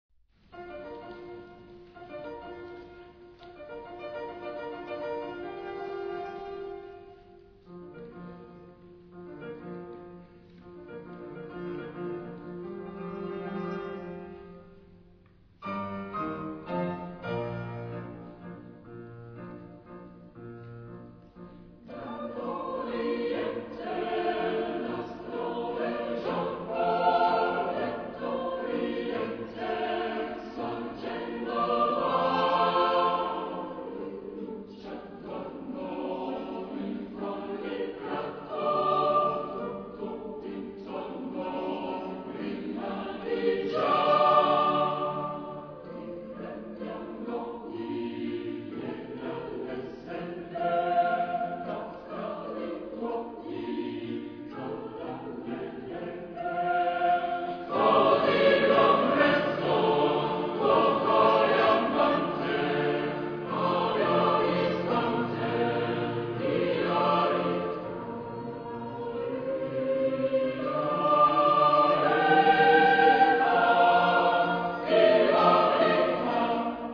Quartetto da camera
Genre-Style-Form: Secular ; Romantic ; Ode
Mood of the piece: lyric
Type of Choir: SATB  (4 mixed voices )
Instruments: Piano (1)
Tonality: A major